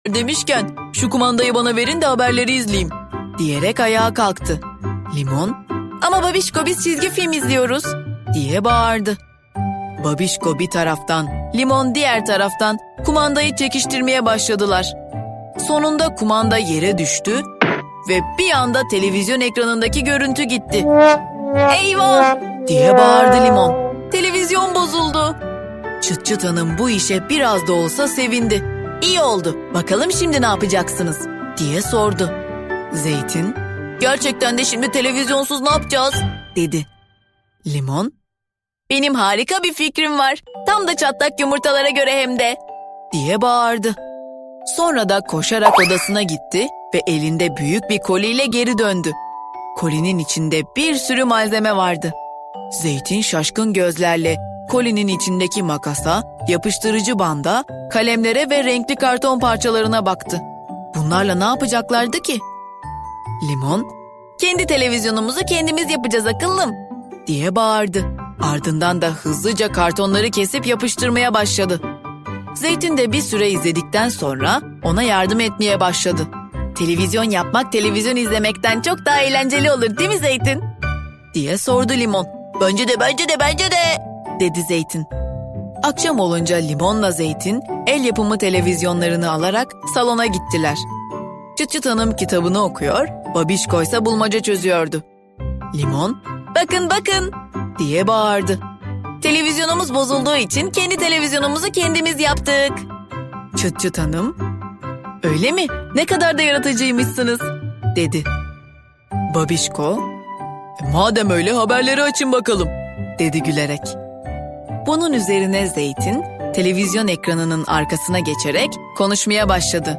Limon ile Zeytin | TV – Abla Sesinden